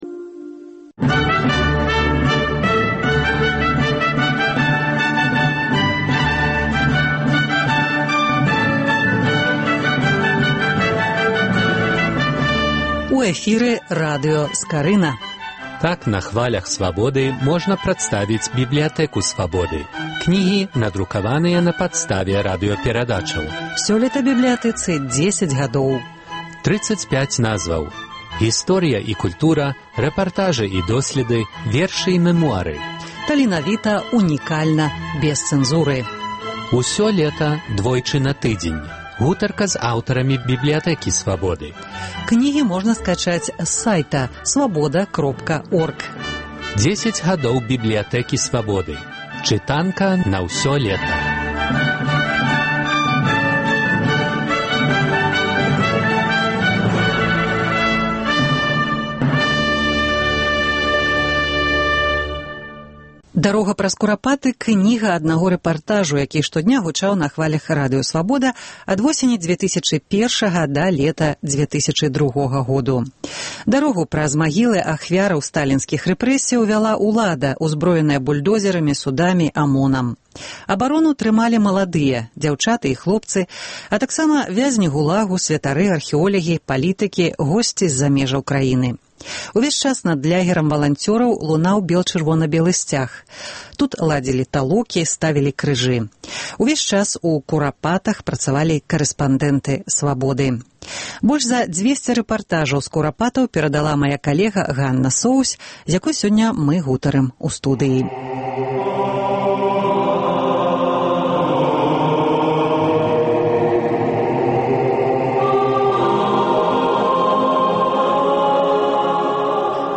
У перадачы гучаць архіўныя запісы.